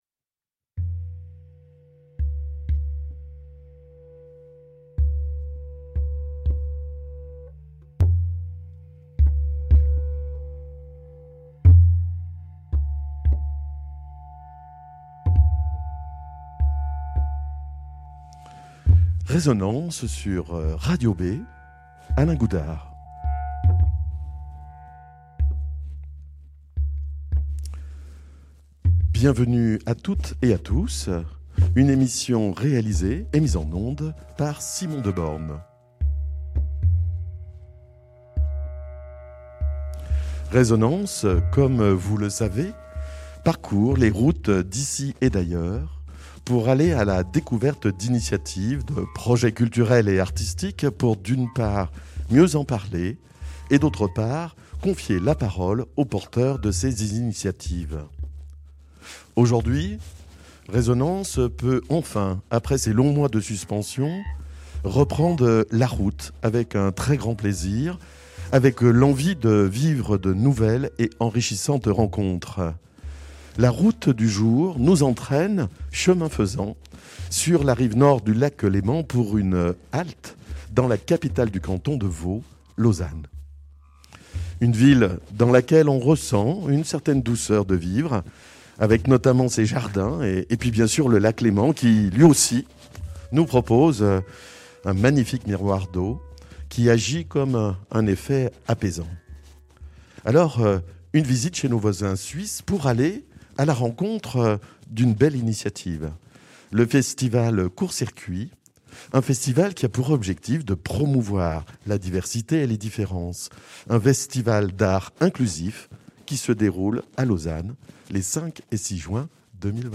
La route du jour nous entraine, chemin faisant, sur la rive nord du Lac Léman pour une halte dans la capitale du canton de Vaud : Lausanne.